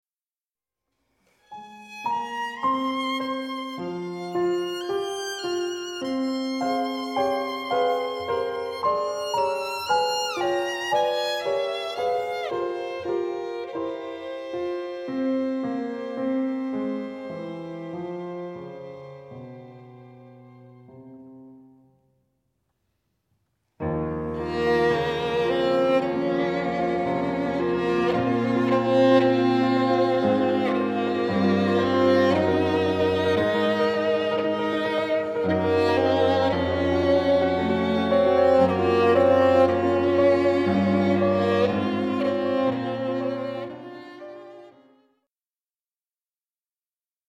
Sonate für Violine und Klavier f-moll BWV 1018